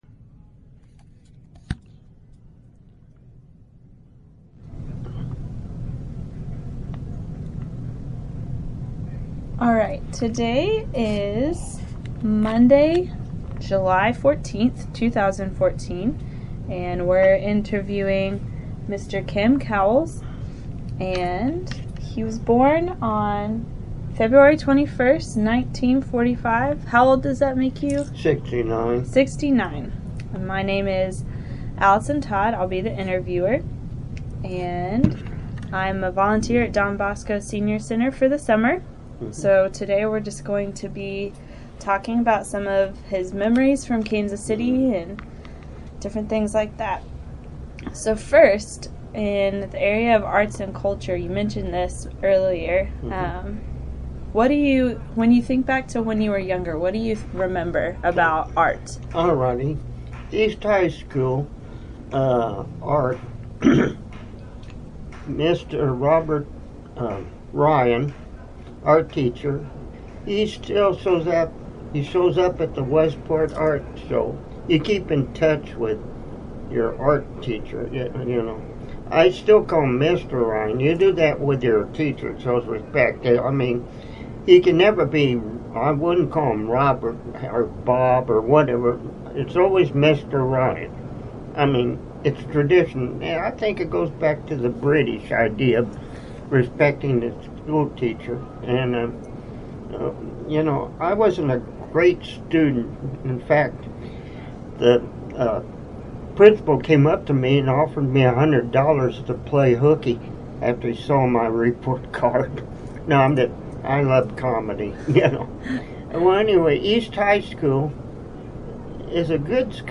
Interviewee Birth Year: 1945